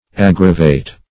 Aggravate \Ag"gra*vate\, v. t. [imp.